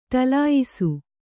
♪ ṭalāyisu